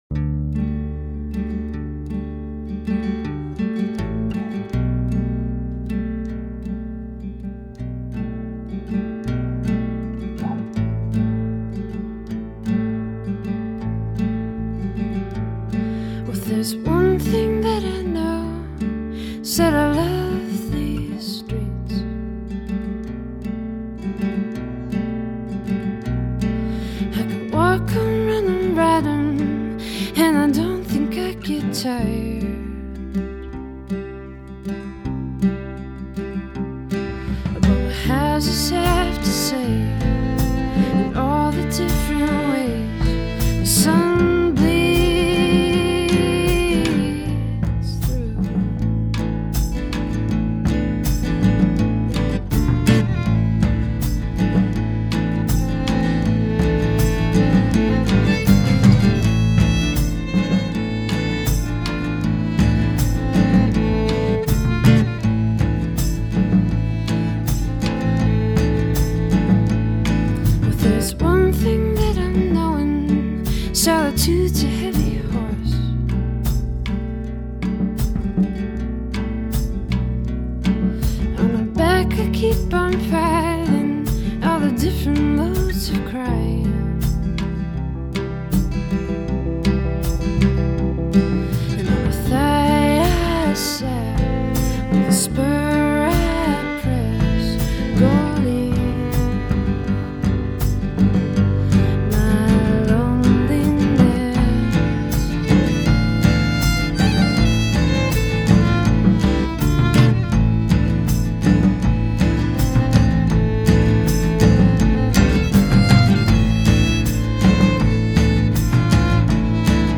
minimalist folk
indie rock